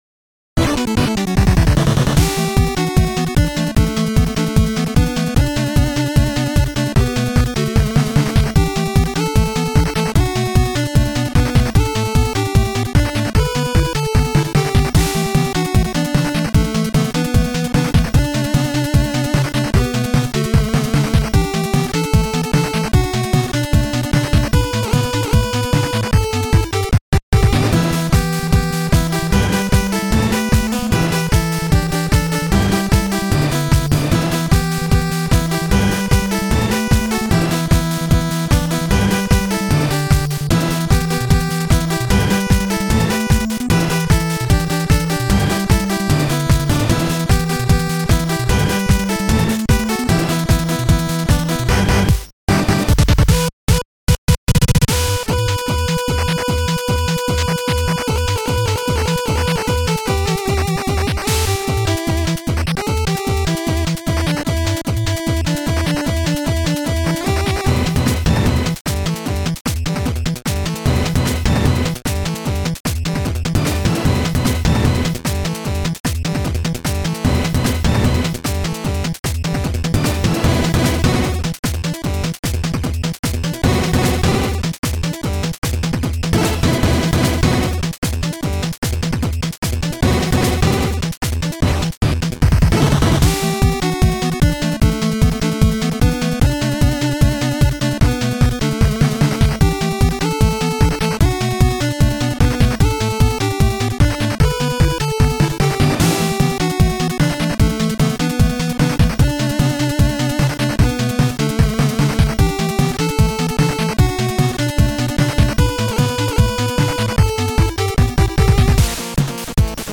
クソアツい、バカカッコ良い予告ムービーの音声改変。
効果音がありものを集めただけなのに少なくてスミマセン。
ハイテンポで音がでかいから気をつけろ。
FC音源
Chiptune